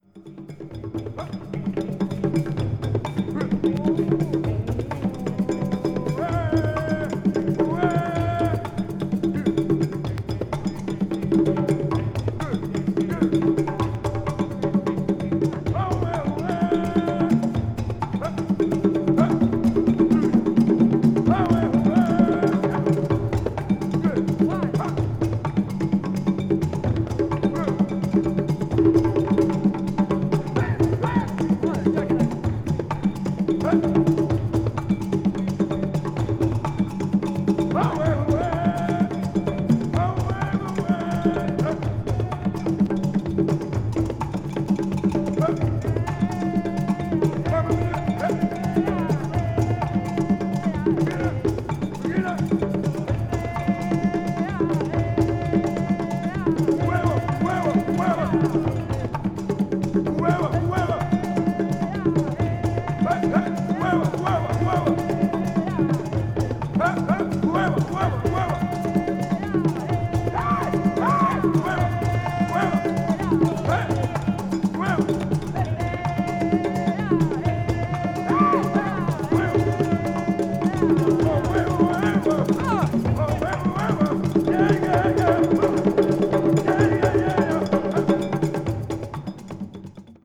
media : VG+/VG+(薄いスリキズによる軽いチリノイズ/プチノイズが入る箇所あり)
もちろんライヴ・レコーディングです。
avant-garde   ethnic jazz   experimental   world music